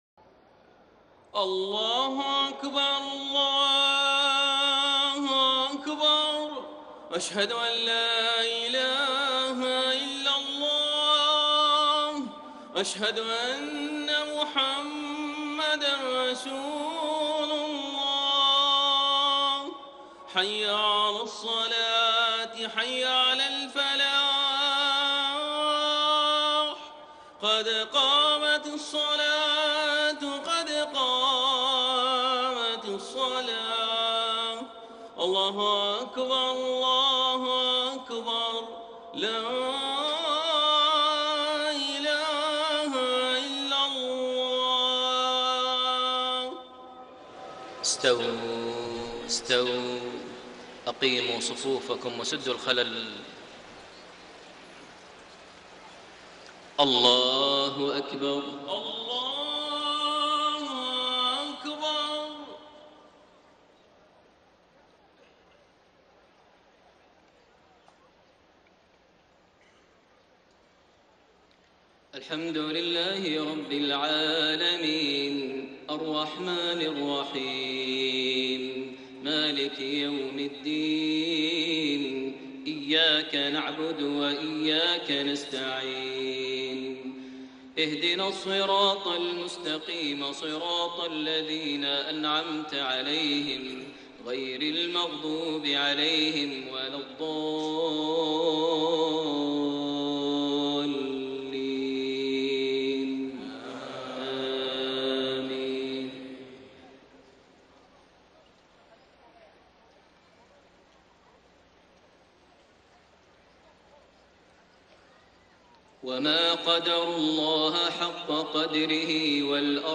صلاة العشاء 8 شعبان 1433هـ خواتيم سورة الزمر 67-75 > 1433 هـ > الفروض - تلاوات ماهر المعيقلي